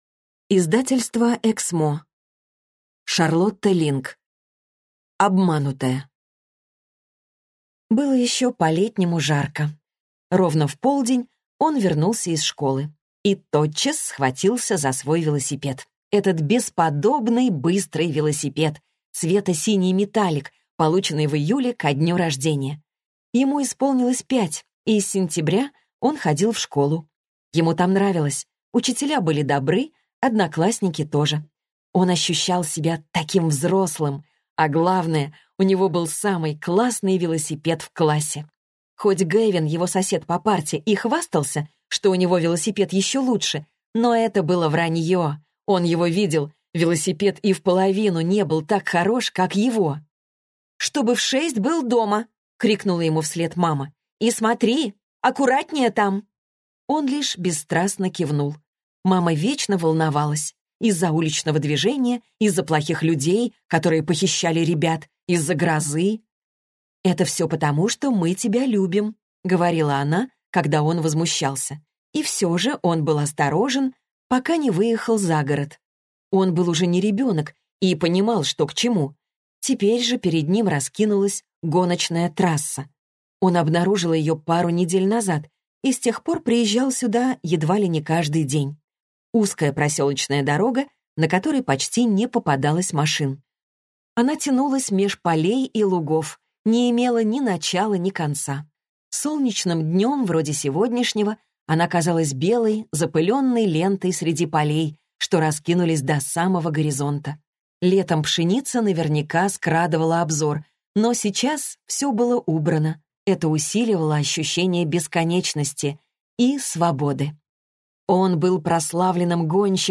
Аудиокнига Обманутая | Библиотека аудиокниг